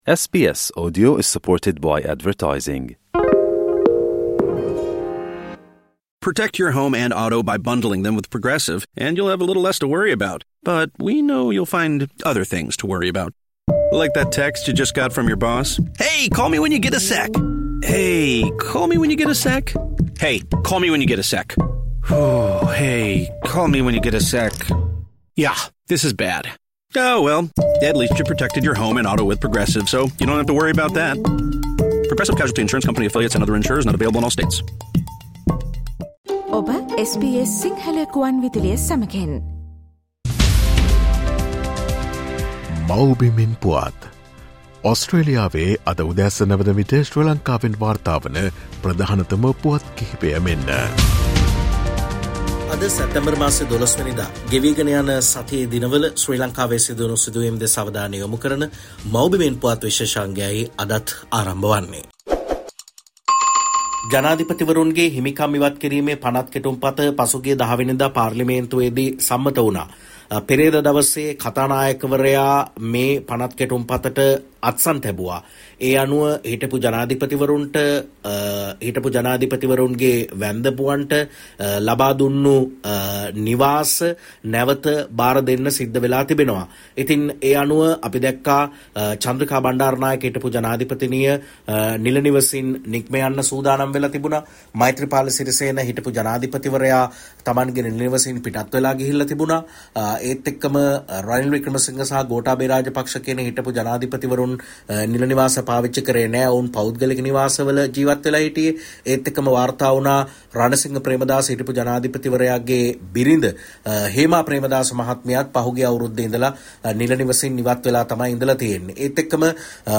මේ සතියේ ශ්‍රී ලංකාවෙන් වාර්තා වූ උණුසුම් හා වැදගත් පුවත් සම්පිණ්ඩනය.